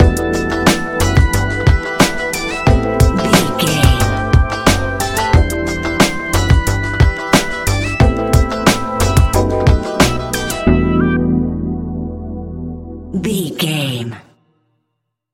Ionian/Major
E♭
laid back
Lounge
sparse
new age
chilled electronica
ambient
atmospheric